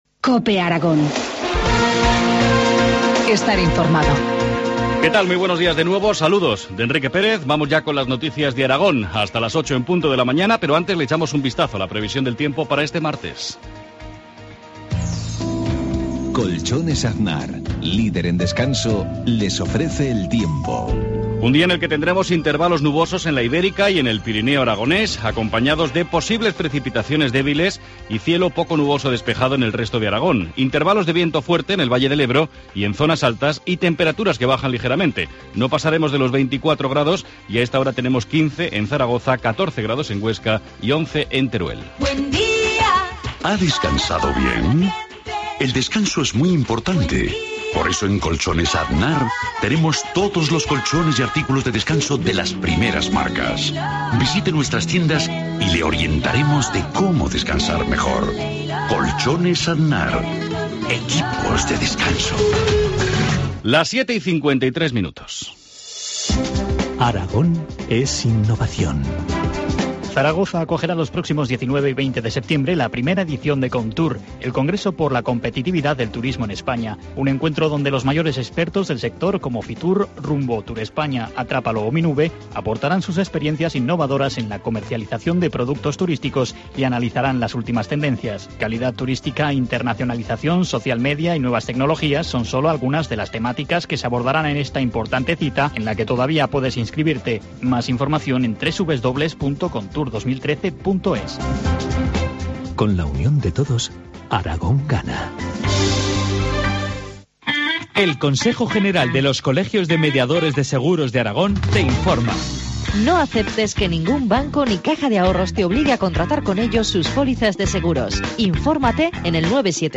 Informativo matinal, martes 17 de septiembre, 7.53 horas